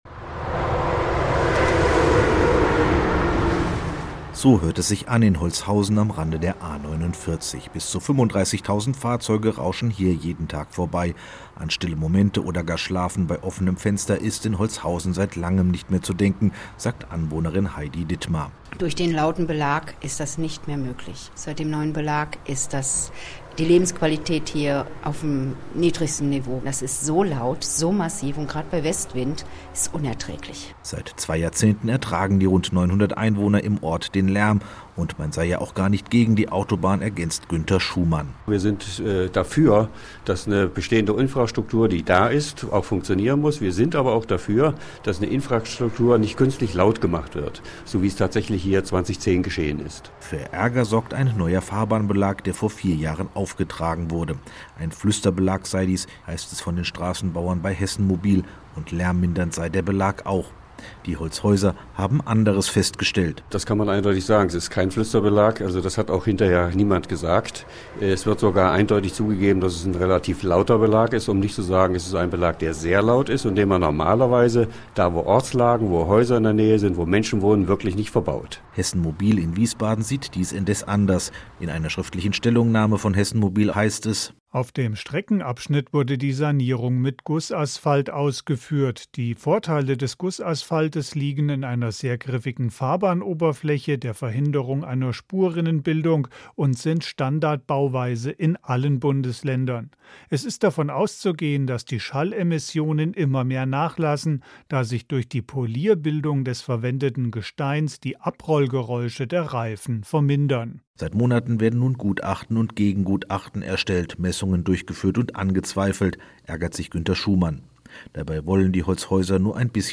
Als ‘musikalische Untermalung’ gab die A 49 alles …